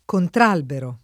[ kontr # lbero ]